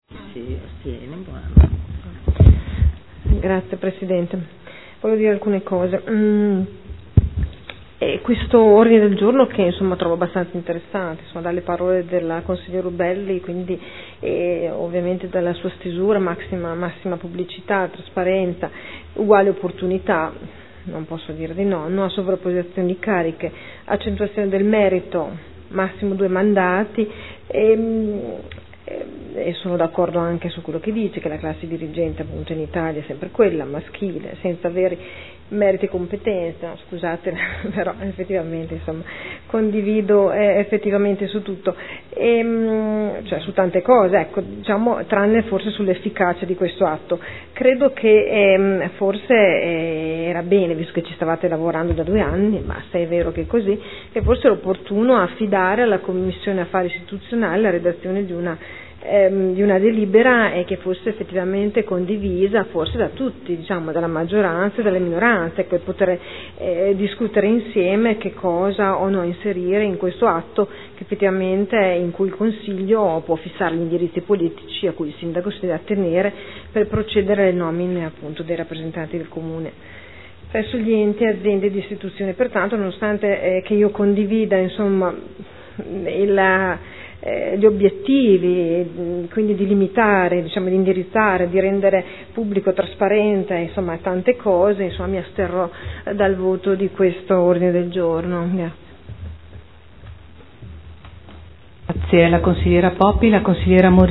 Sandra Poppi — Sito Audio Consiglio Comunale
Seduta del 05/12/2013.